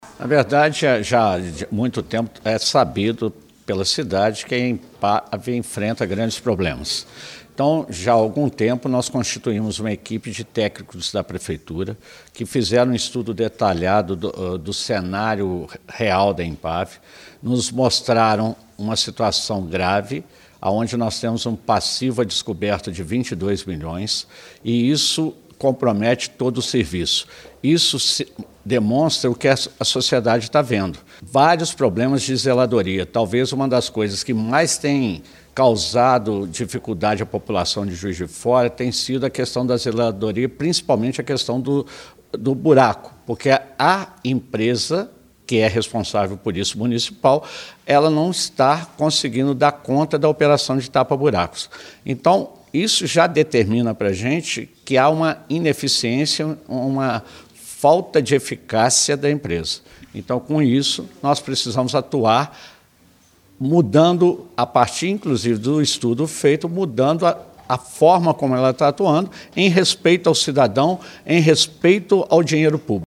O anúncio foi feito em coletiva, na manhã desta sexta-feira, 16, pelo prefeito Antônio Almas.
Segundo o prefeito Antônio Almas, a reestruturação da Empav consiste na segunda fase da Reforma Administrativa. Ele explica a necessidade de mudanças.